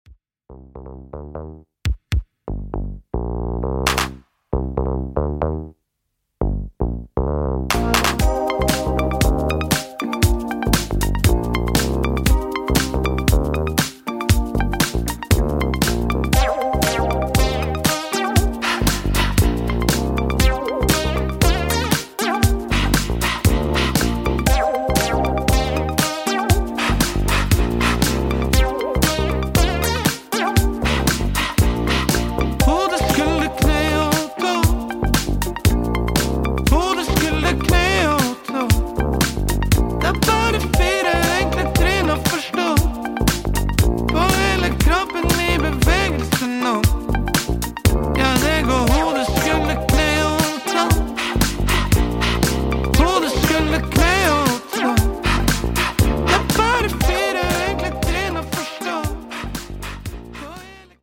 Off kilter Norwegian afro-disco